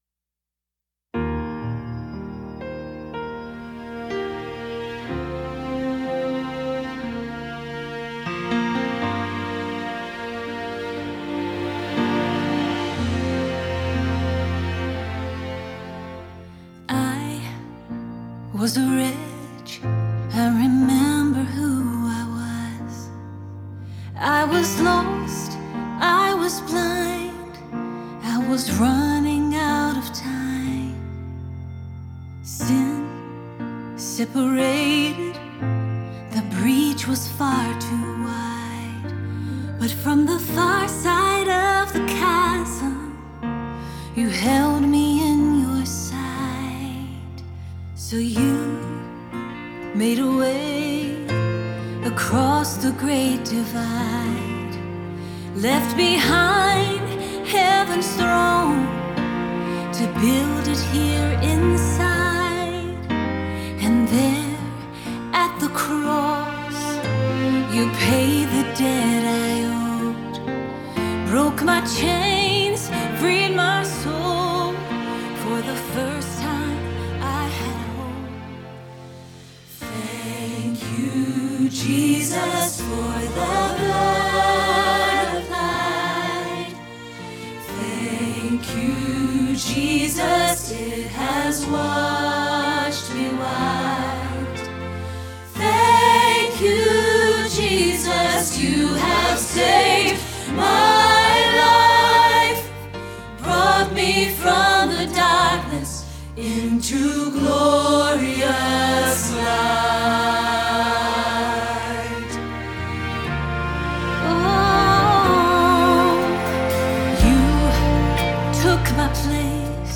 Adult Easter Choir Music
soprano
Thank-You-Jesus-Soprano-demo.mp3